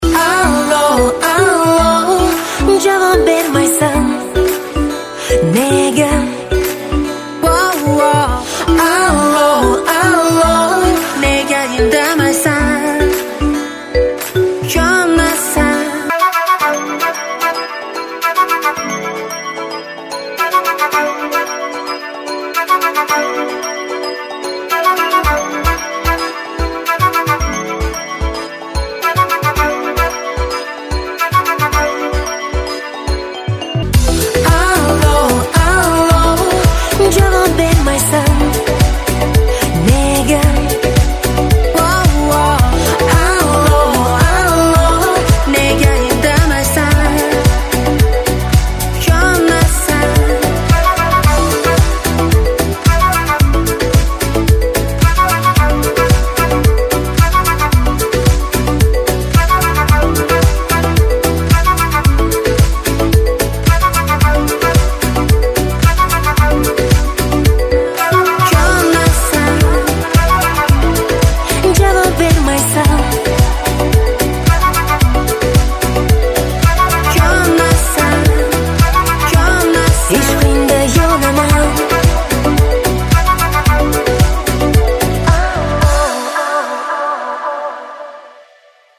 • Качество: 128, Stereo
красивые
спокойные
узбекские
хорошая песня узбекских исполнителей